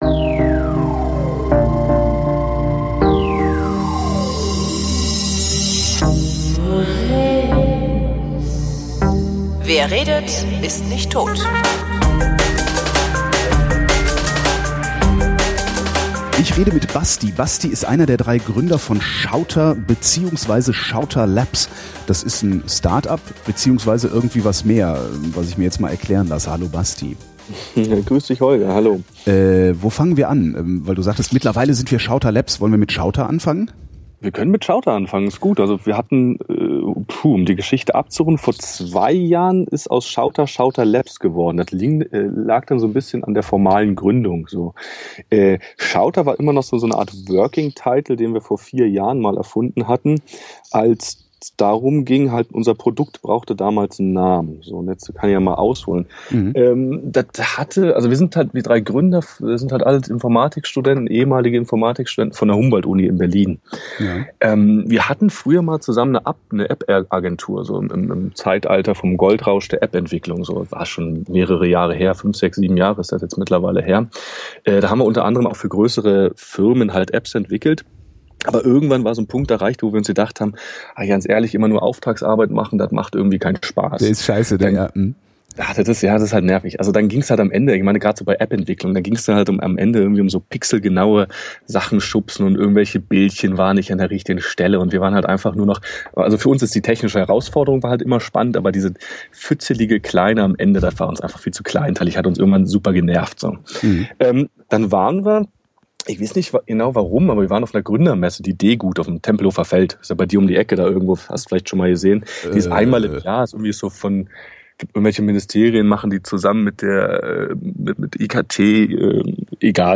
Damit das ganze nicht völlig blödsinnig inszeniert aussieht, haben wir eine echte, komplette Sendung aufgezeichnet. Daraus ist ein launiges Gespräch geworden und ich dachte, das kann ich ja auch ruhig mal veröffentlichen.